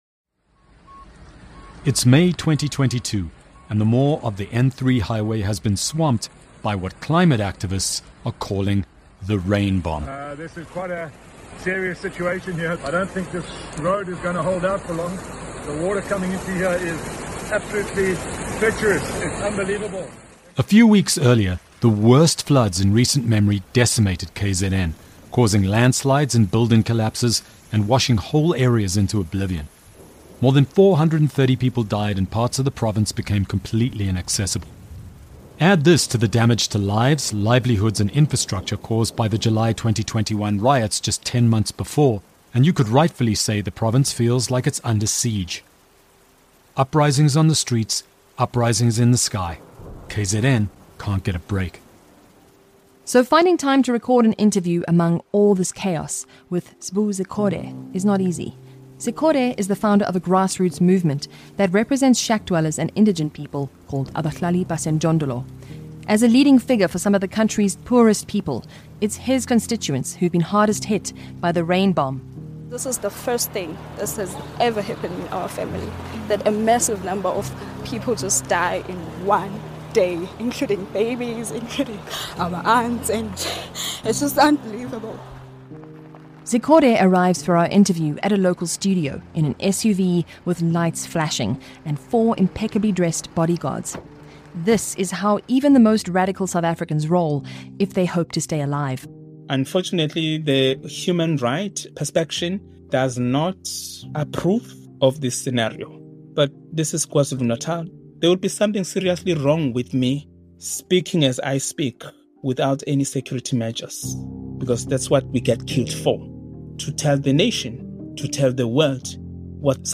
Featuring: - Dr Zweli Mkhize, former Premier of KwaZulu-Natal, former ANC Treasurer-General, former Minister of Health